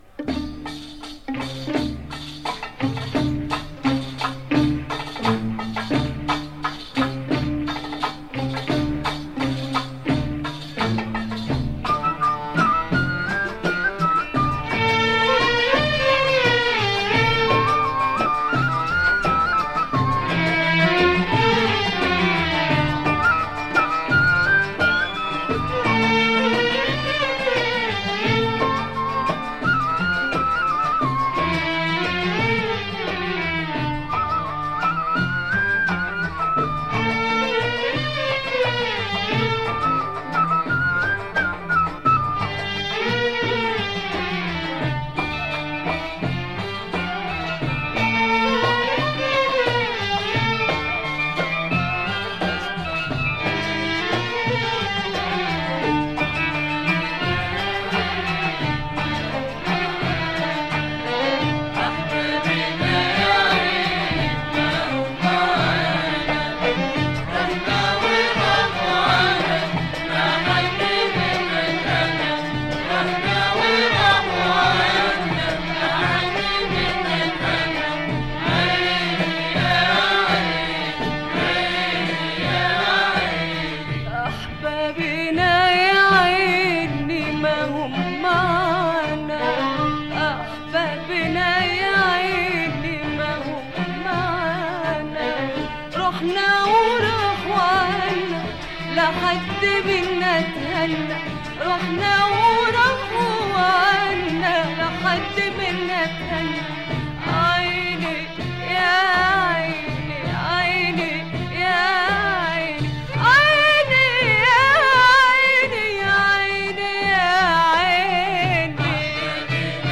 Egyptian album by female singer